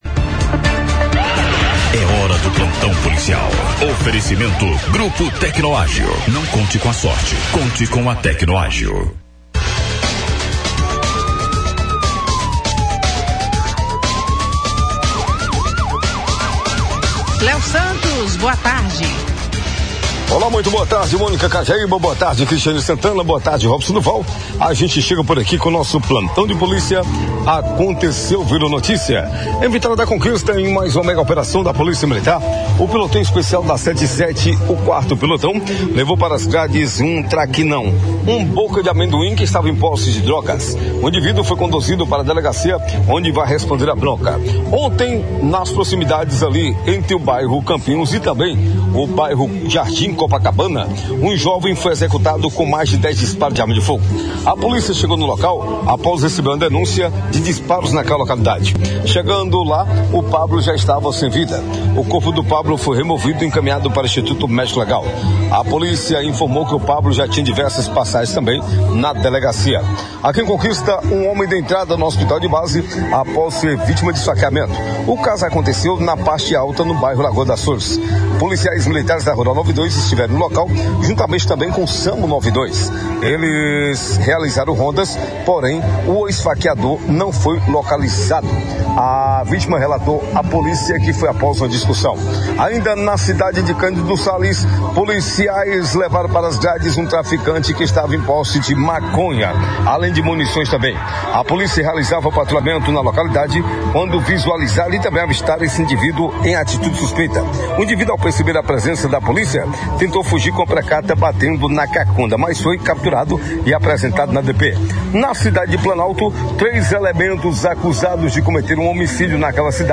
Uma reportagem da Rádio Clube de Conquista, reproduzida pelo BLOG DO ANDERSON nesta segunda-feira (11), traz detalhes sobre os assassinatos que estão sendo investigados pela Delegacia de Homicídios e Proteção à Pessoa (DHPP).